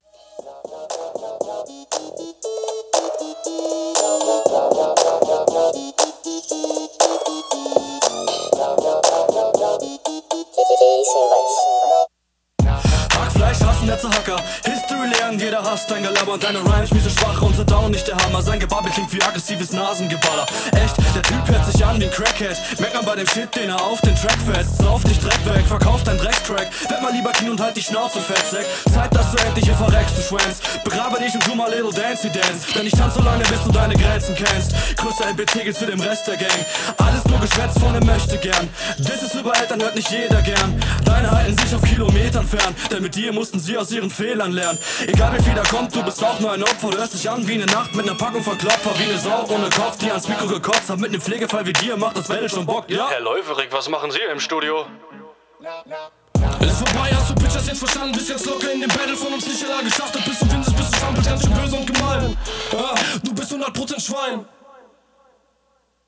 mix is nich gut versuch mal mit Sidechain EQ auf dem Instrumental zu arbeiten DU …